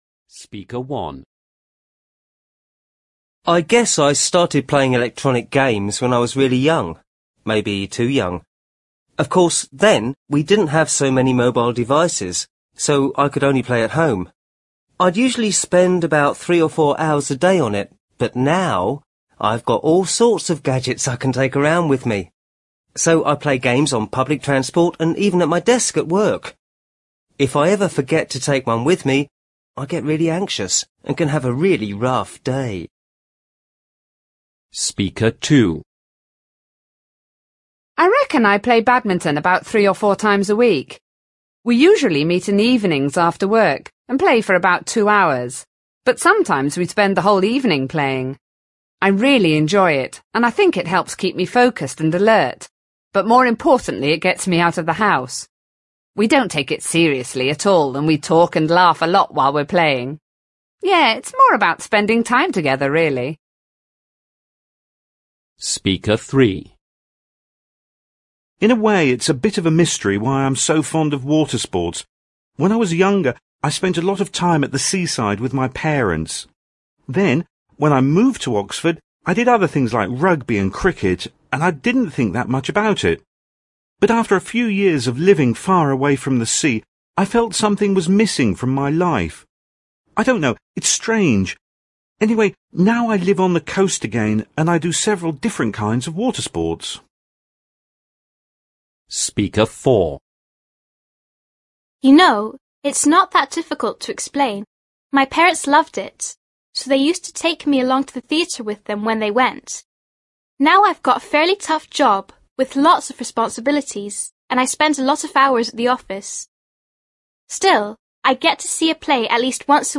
You’ll hear 6 different speakers talking about what they do in their free time.